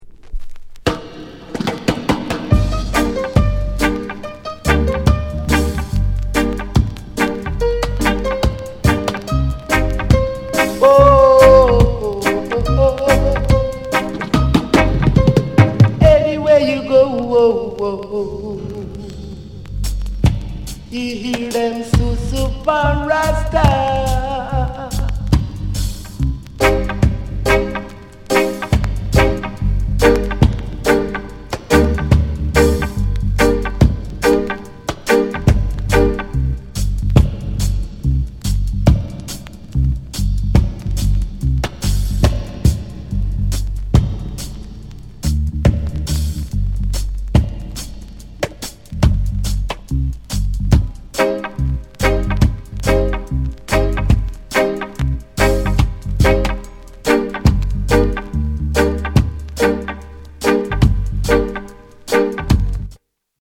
SOUND CONDITION A SIDE VG
DEE JAY CUT